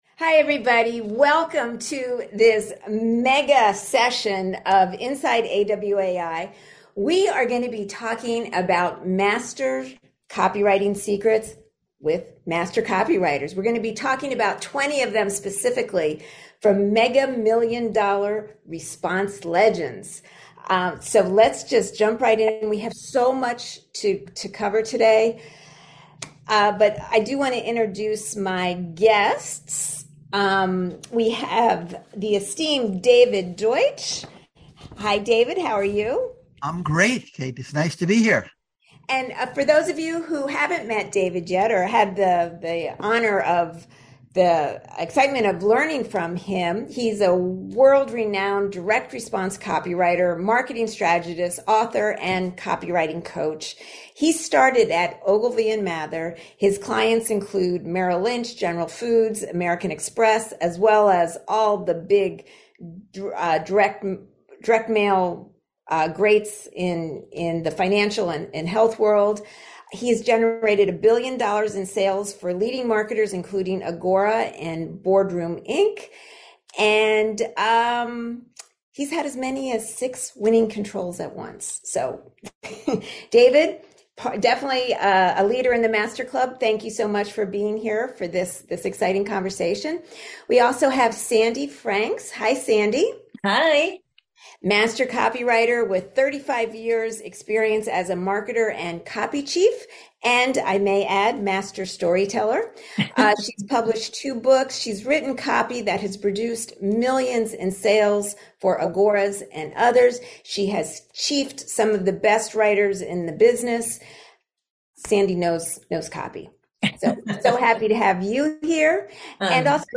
Our panel of direct-response leaders shares their 20 deepest discoveries from legends and today's A-list writers — with important take-aways for you.
Inside AWAI Webinar and Q&A: 20 Copywriting Secrets from Mega-Million-Dollar Direct-Response Legends